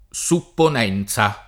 supponenza [ S uppon $ n Z a ]